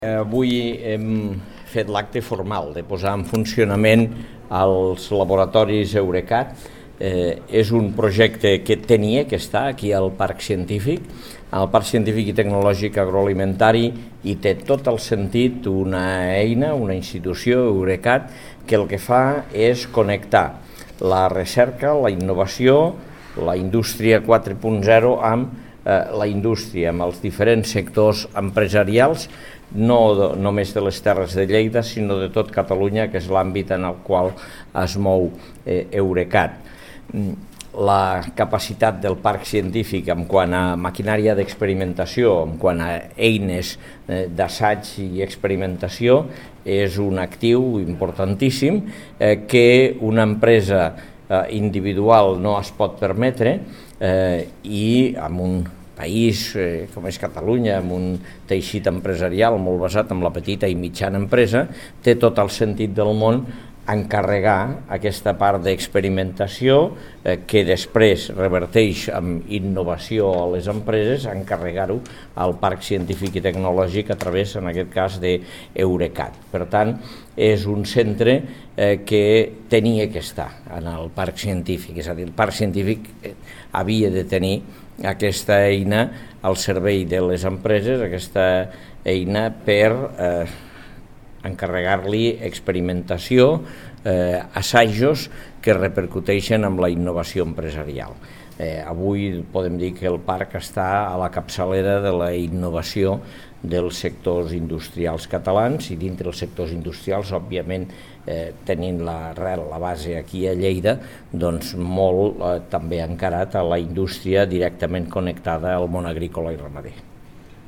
tall-de-veu-de-lalcalde-angel-ros-sobre-les-noves-instal-lacions-deurecat-al-parc-cientific-de-lleida